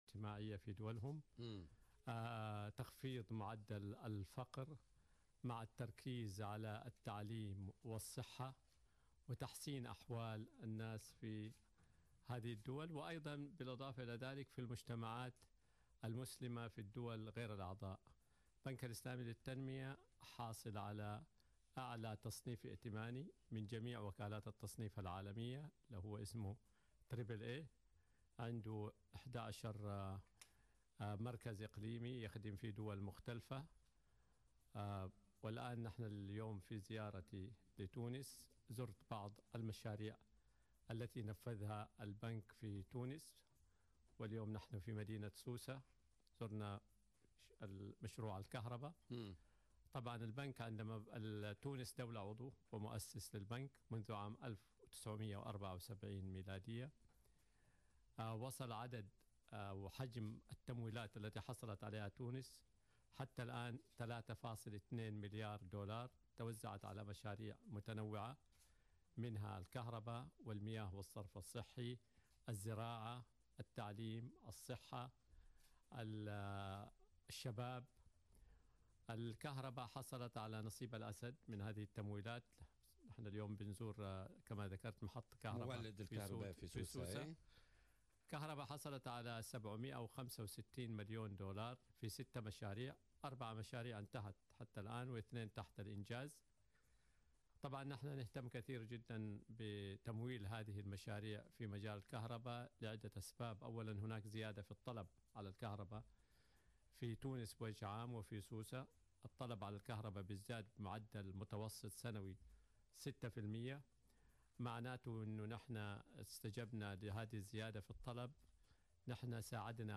أكد رئيس مجموعة البنك الاسلامي للتنمية بندر الحجار ضيف بوليتيكا اليوم الجمعة 9 مارس 2018 أن حجم التمويلات التي حصلت عليها تونس حتى الان من البنك السلامي بلغت حوالي2 3. مليار دولا توزعت على مشاريع متنوعة منها الكهرباء والمياه والصرف الصحي ,الزراعة ,التعليم والصحة .